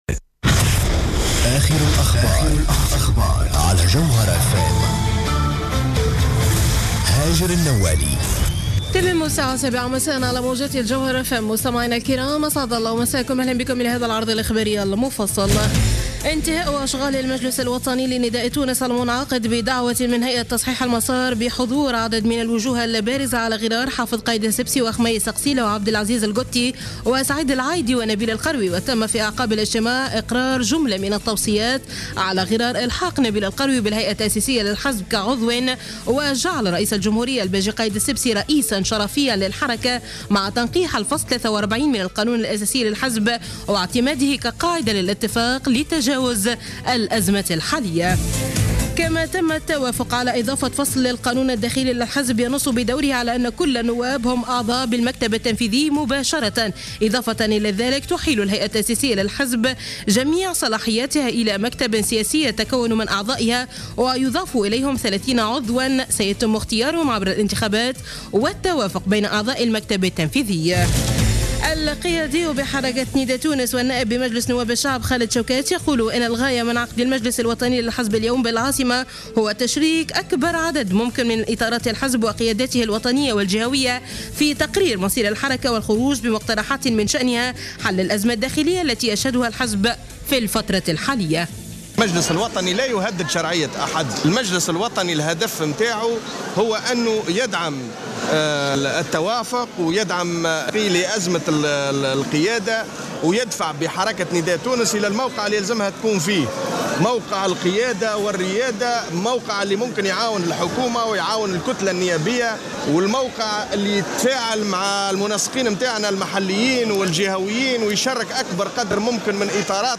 نشرة أخبار السابعة مساء ليوم السبت 14 مارس 2015